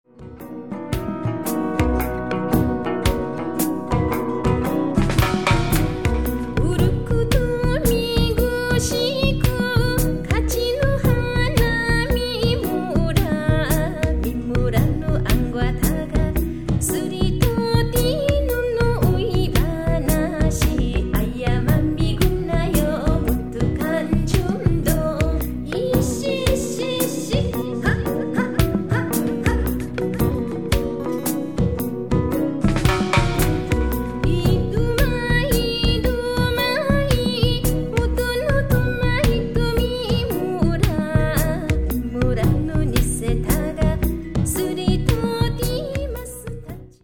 ※沖縄の波の音のBGM収録。ジャンル（沖縄、レゲエ、ハワイアン）